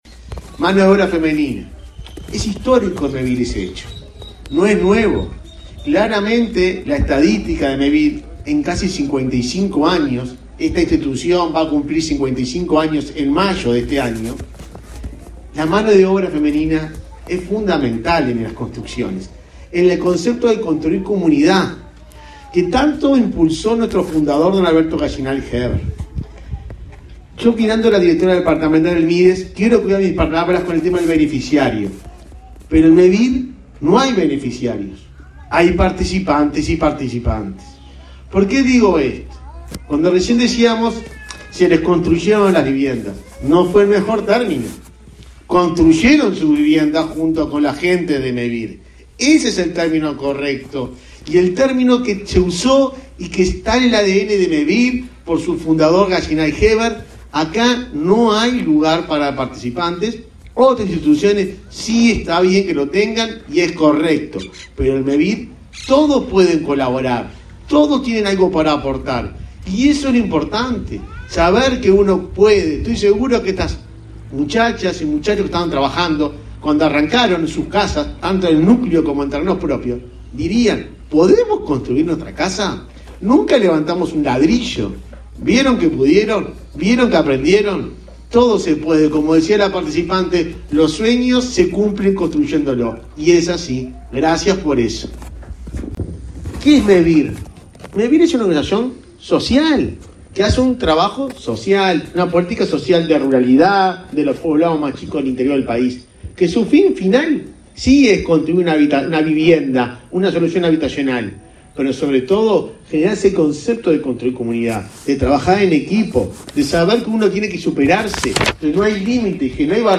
Palabras del presidente de Mevir, Juan Pablo Delgado
Palabras del presidente de Mevir, Juan Pablo Delgado 16/02/2022 Compartir Facebook X Copiar enlace WhatsApp LinkedIn El presidente de Mevir, Juan Pablo Delgado, encabezó, el pasado 15 de febrero, la inauguración de viviendas en Santa Clara de Olimar, departamento de Treinta y Tres.